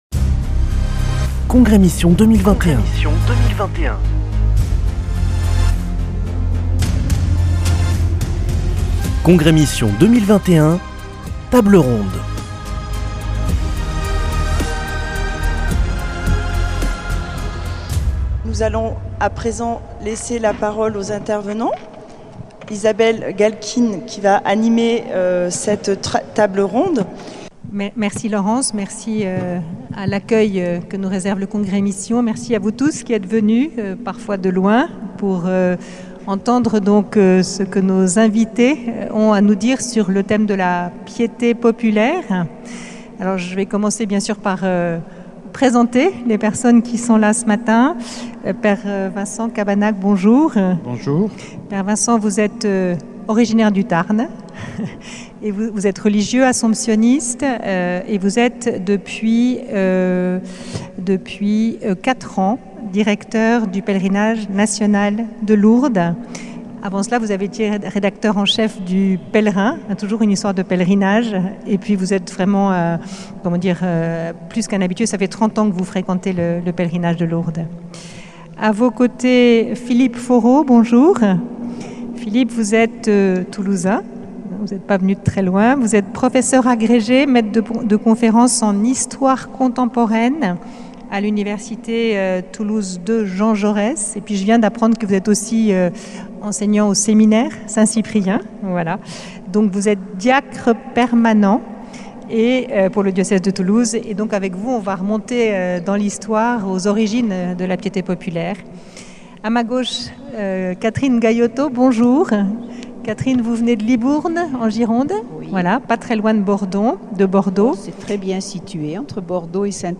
Congrès Mission du 1er au 3 octobre à Toulouse - Table ronde 5